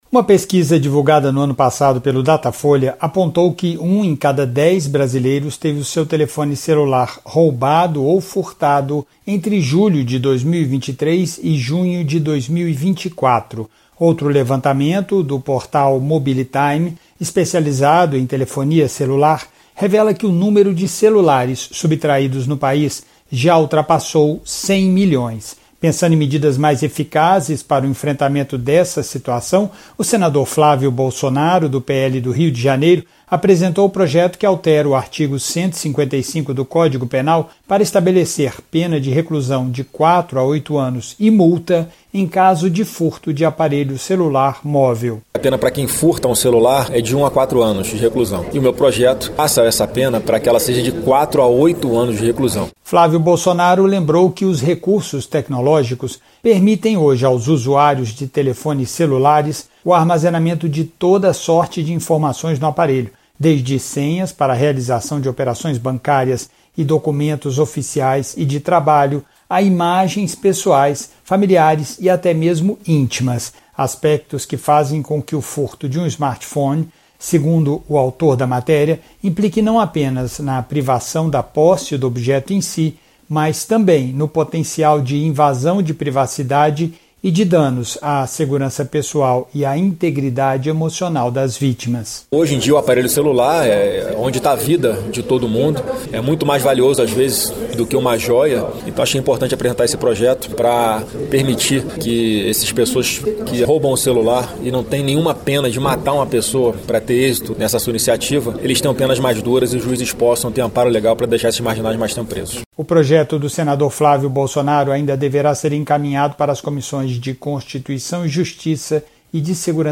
Flávio Bolsonaro disse que ''a vida de todo mundo está hoje no aparelho celular'', e que é preciso dar amparo legal para que os juízes deixem os marginais que cometem esse crime presos por mais tempo.